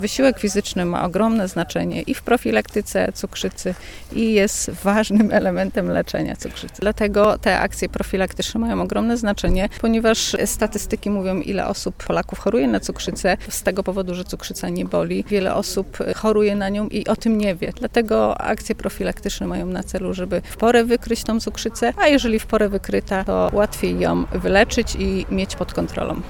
Z tej okazji w żagańskim Parku Książęcym zorganizowane zostało spotkanie plenerowe. Jego celem jest uświadamianie mieszkańców o tym, jak ważne są badania profilaktyczne.